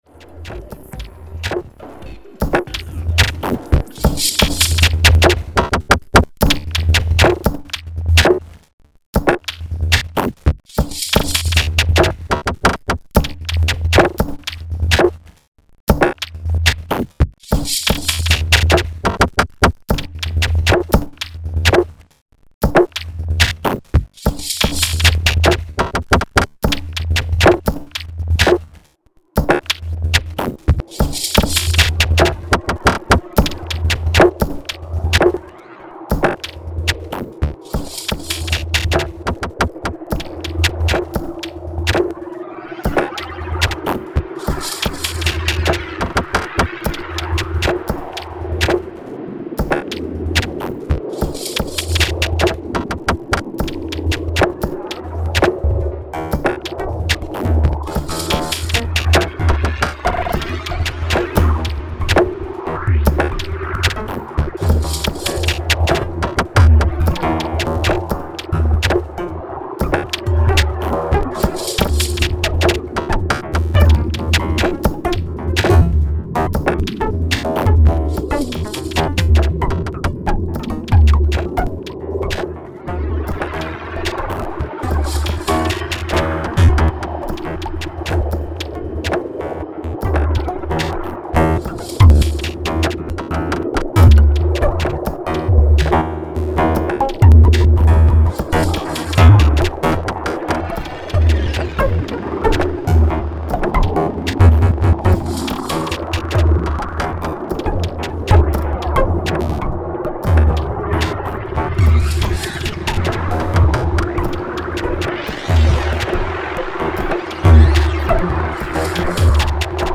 Alien Spring 2, Improvisation